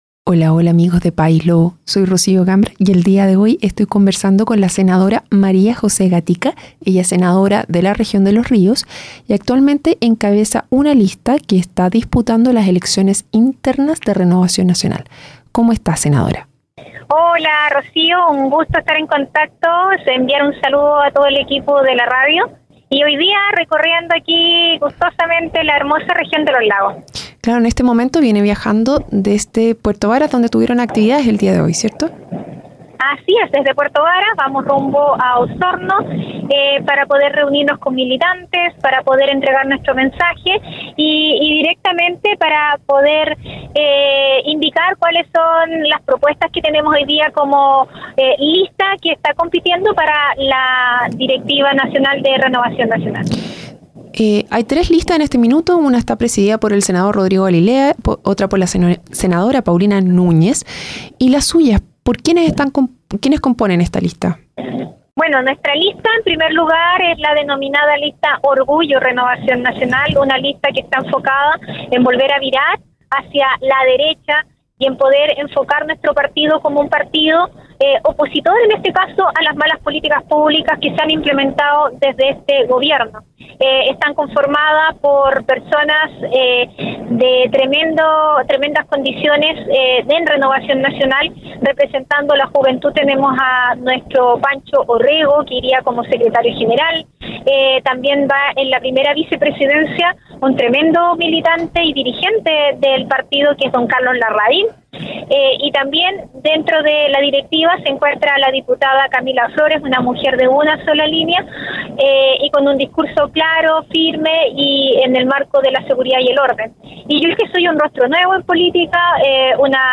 programa de entrevistas
En su emisión más reciente, conversó la Senadora María José Gatica Bertin.